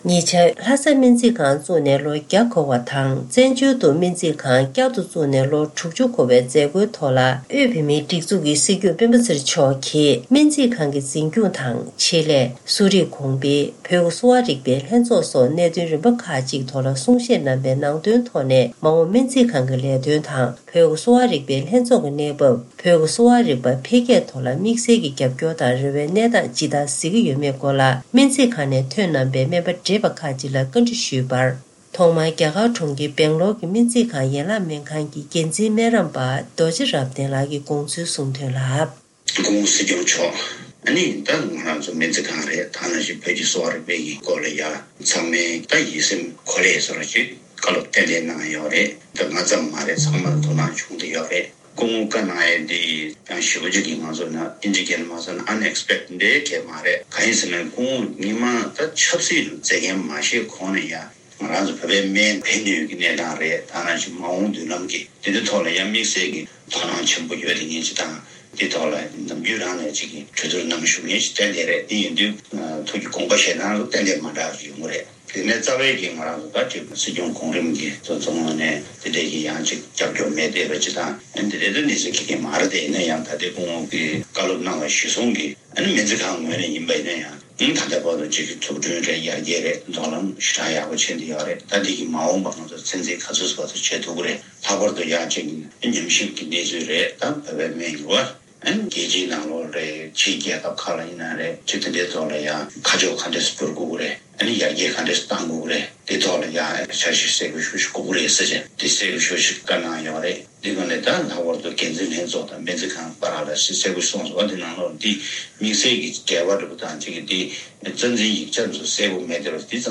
ཉེ་ཆར་སྨན་རྩིས་ཁང་དུ་སྲིད་སྐྱོང་མཆོག་གིས་གནང་བའི་གསུང་བཤད་ཐད་སྨན་རྩིས་ཁང་ནས་ཐོན་པའི་སྨན་པ་བགྲེས་པ་ཁག་ཅིག་གིས་དགོངས་ཚུལ་གསུངས་བ།